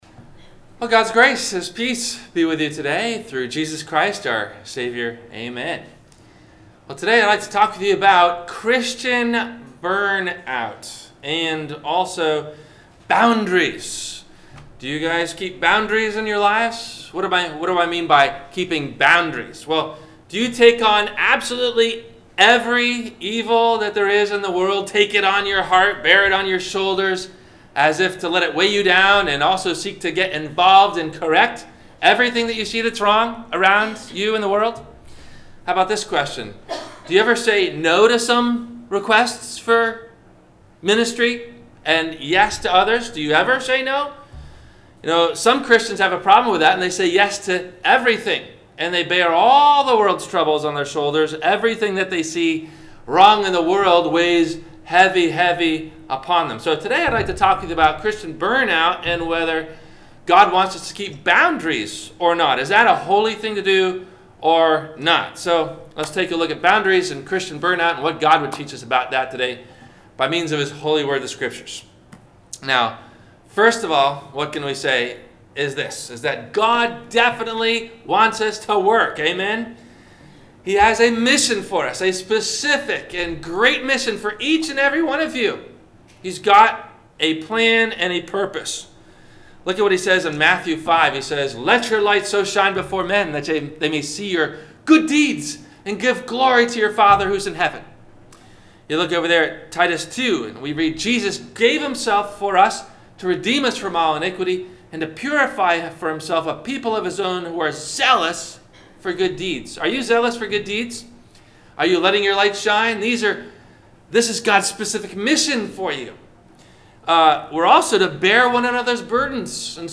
Christian Burnout & Keeping Boundaries - Sermon - April 29 2018 - Christ Lutheran Cape Canaveral